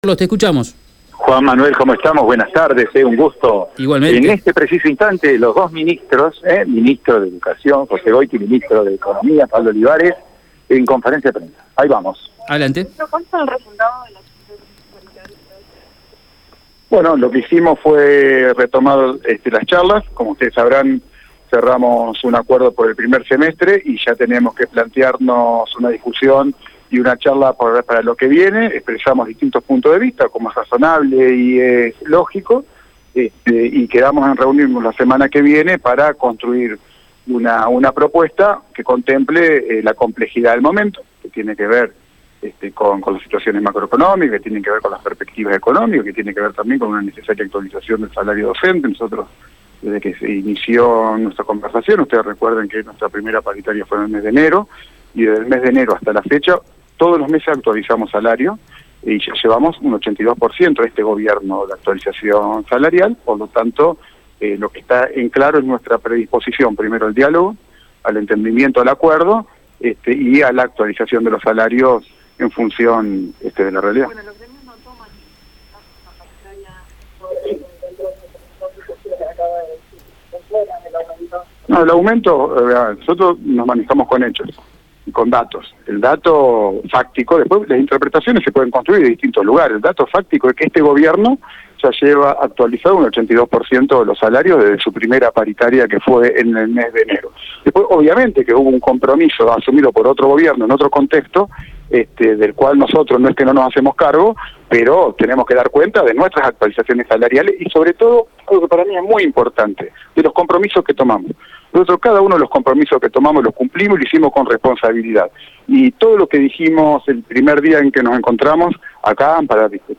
Escucha la palabra de los ministros en Radio EME: